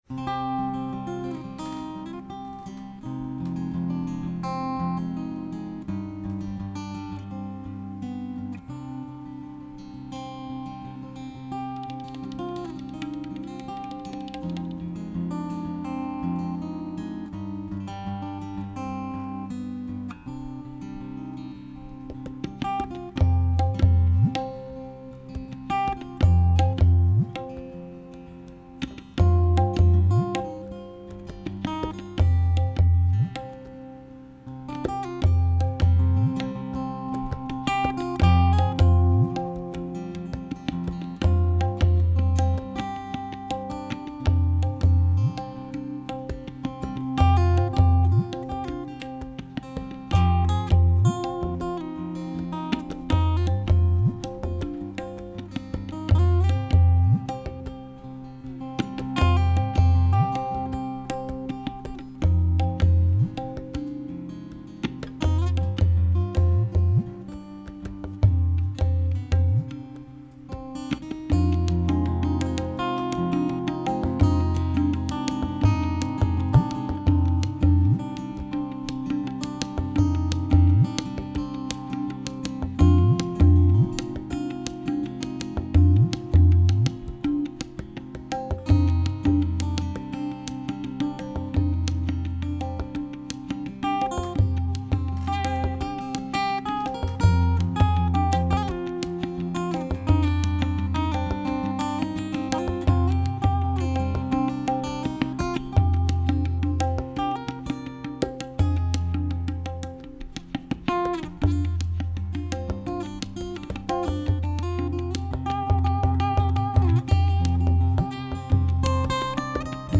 Live at UNH · CD Release Show 10/26/03
September's download is a live performance
Performed on acoustic guitar and tabla